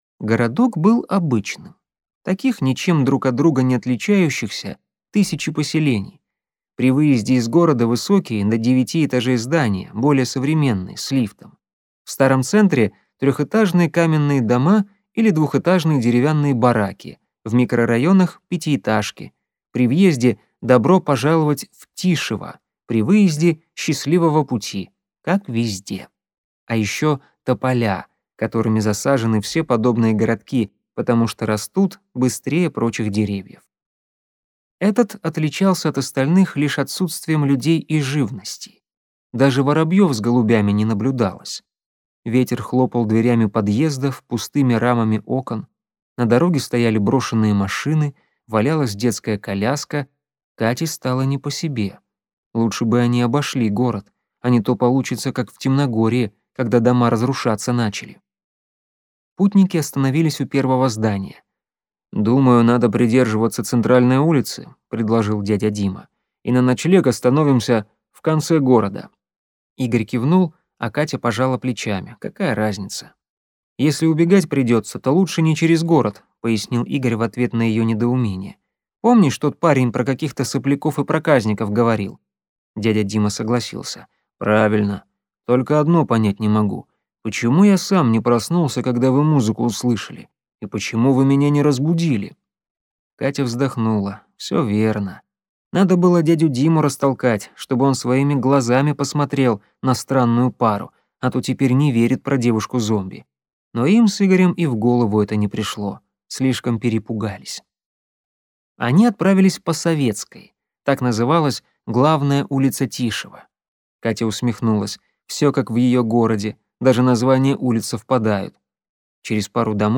Аудиокнига Темногорье. Плацкартный билет | Библиотека аудиокниг